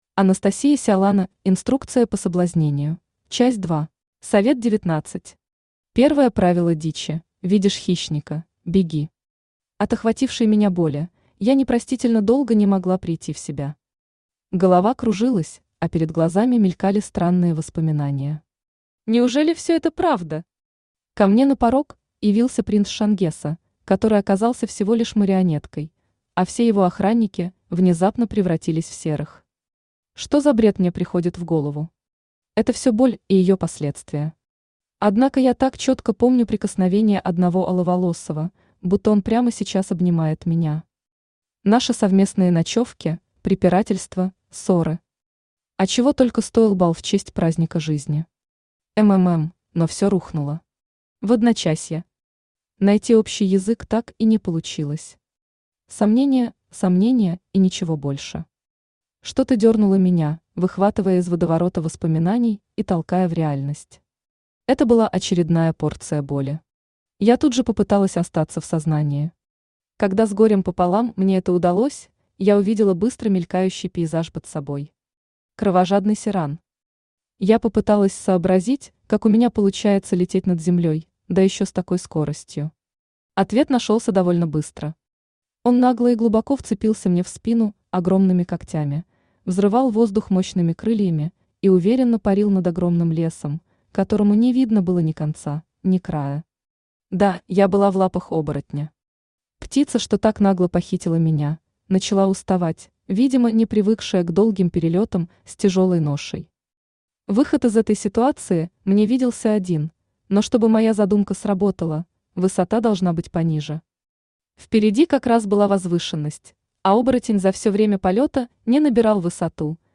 Aудиокнига Инструкция по соблазнению. Часть 2 Автор Анастасия Сиалана Читает аудиокнигу Авточтец ЛитРес. Прослушать и бесплатно скачать фрагмент аудиокниги